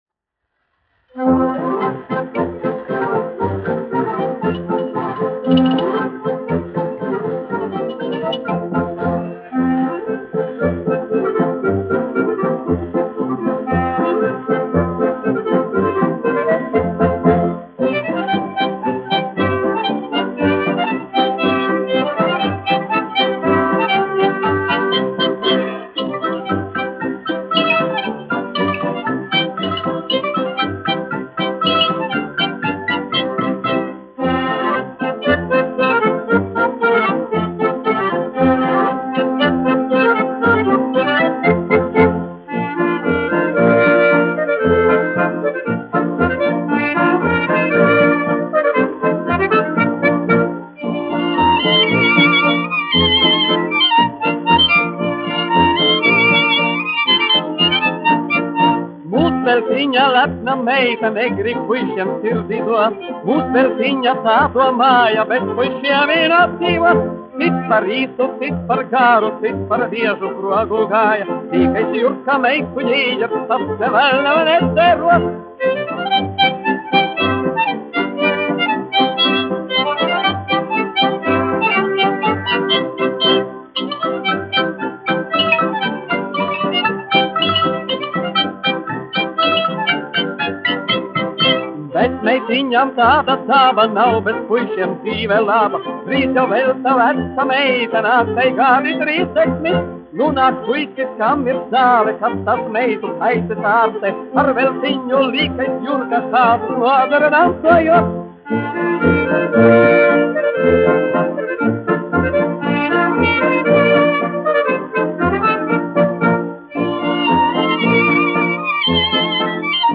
1 skpl. : analogs, 78 apgr/min, mono ; 25 cm
Polkas
Skaņuplate